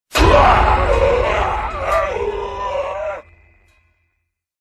Sting Metallic 1 With Zombie
Category: Sound FX   Right: Personal
Tags: Zombie Sound Effects Stingers Guns